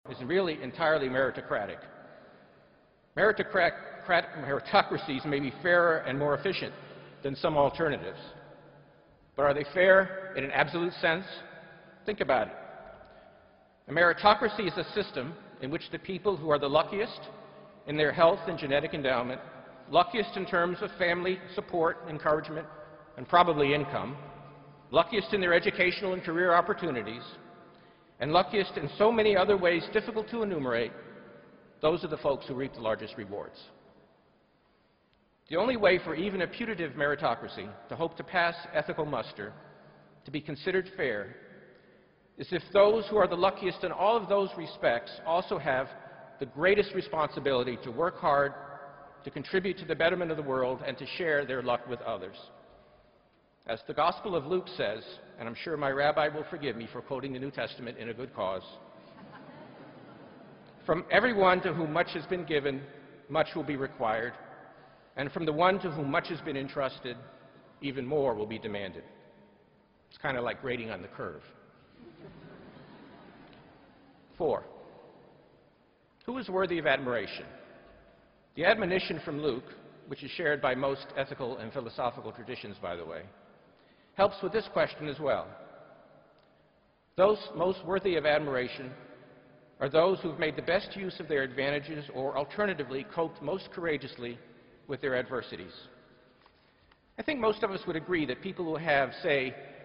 公众人物毕业演讲 第307期:本伯南克2013普林斯顿(6) 听力文件下载—在线英语听力室